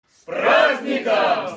Звук людей, выкрикивающих голосом С праздником